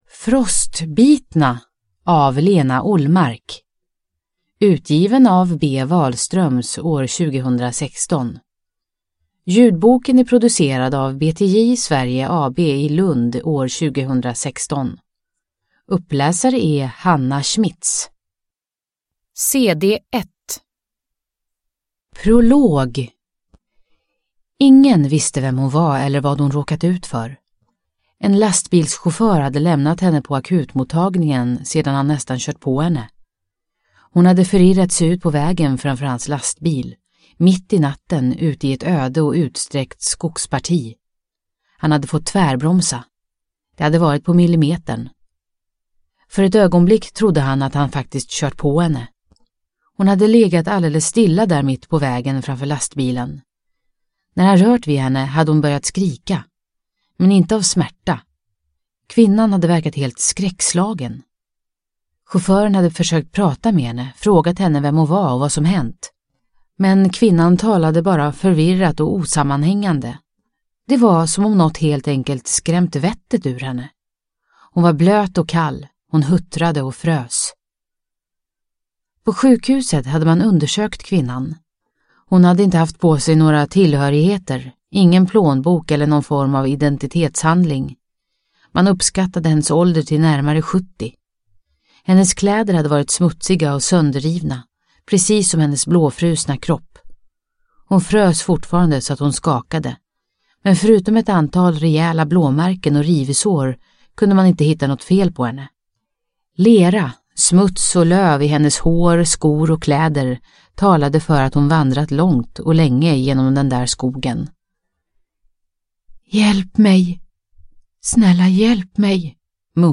Frostbitna – Ljudbok – Laddas ner